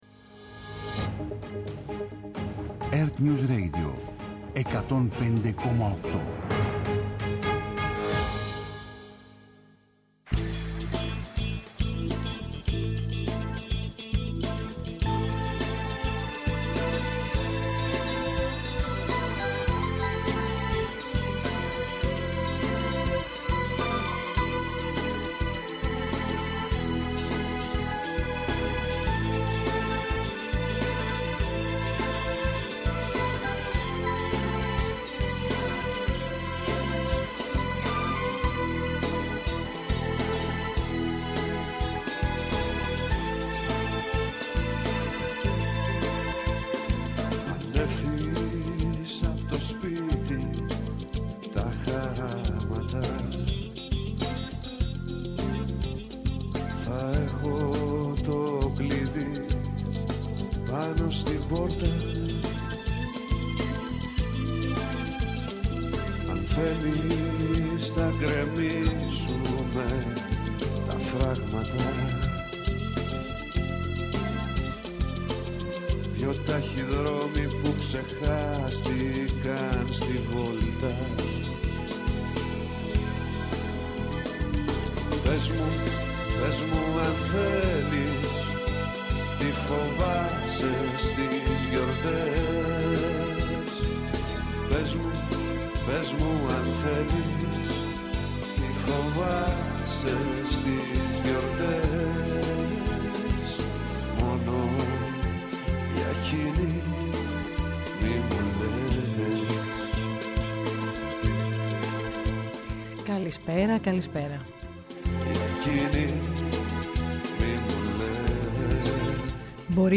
Απόψε, μιλάμε για την σημασία προώθησης, διάσωσης και διάδοσης της ελληνικής γλώσσας, με δύο καλεσμένους που ο καθένας, με τον δικό του τρόπο, μάχεται για το θέμα αυτό : τον Παντελή Μπουκάλα, Συγγραφέα-Ποιητή-Αρθρογράφο και τη Μιμή Ντενίση, Ηθοποιό-Σκηνοθέτη-Μεταφράστρια με αφορμή τη συνολική της δράση, τα γυρίσματα στο Λονδίνο του δραματοποιημένου ντοκιμαντέρ της για τα Γλυπτά του Παρθενώνα και την συμμετοχή της στις συζητήσεις που γίνονται στη Βουλή των Λόρδων για τη διάσωση και την επέκταση του ελληνικού σχολείου στο Cambridge (Greek School of St. Athanasios).